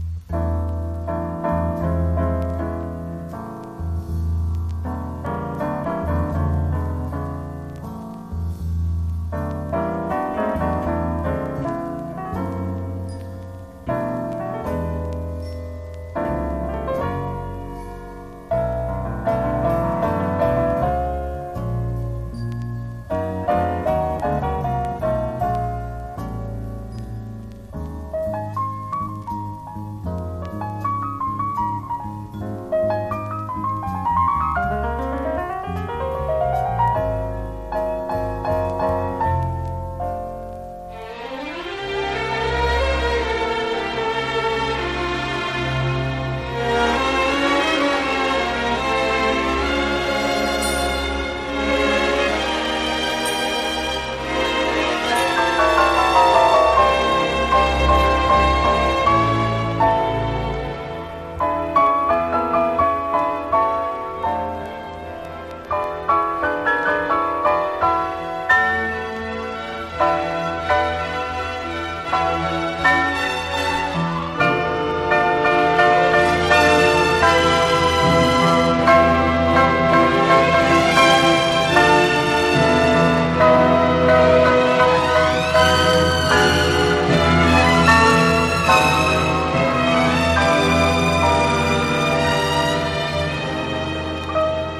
華やかな美麗オーケストラル・ピアノ・ラウンジ大傑作！
ジャズ、クラシック、ロック、カントリー、ブルースなどを横断し